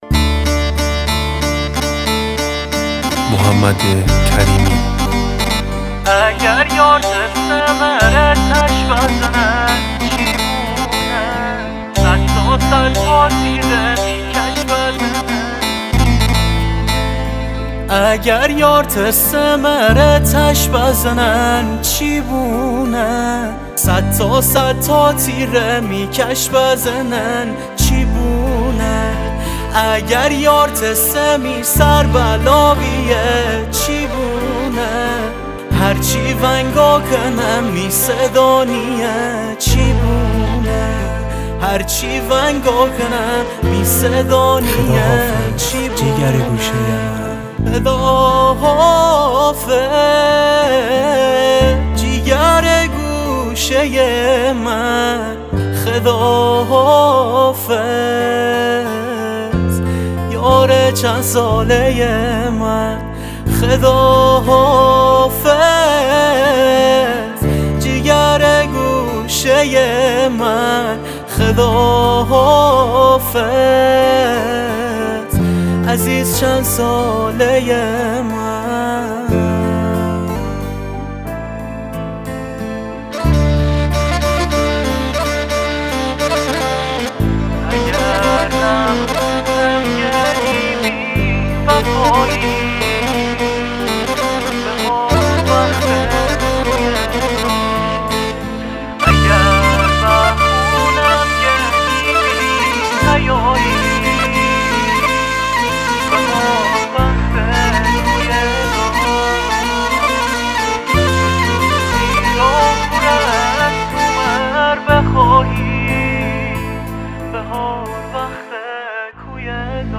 غمگین
آهنگ غمگین مازندرانی